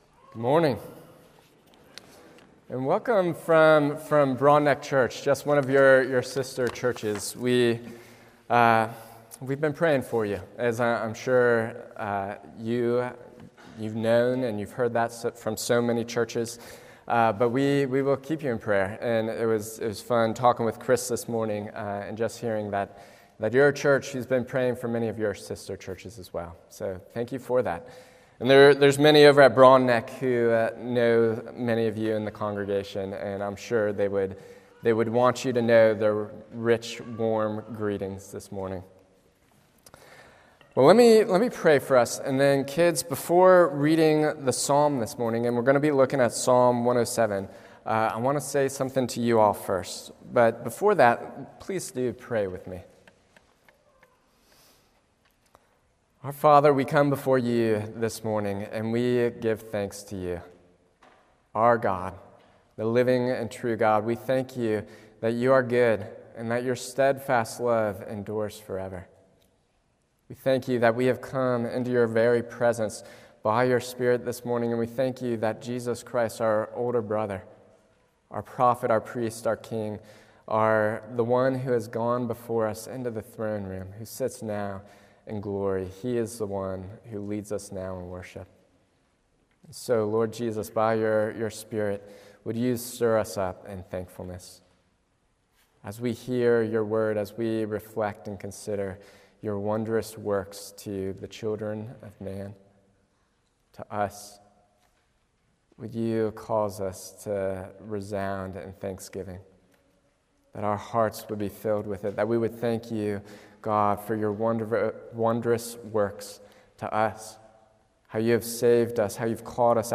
Sermons – Trinity Presbyterian Church
From Series: "Guest Sermons"